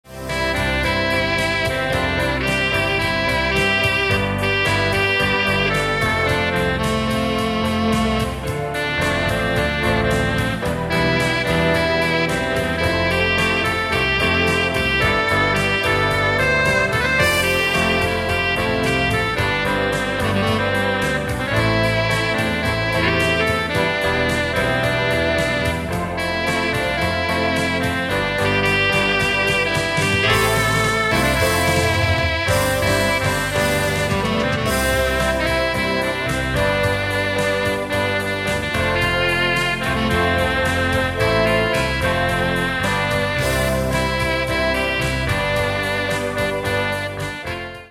80's Rock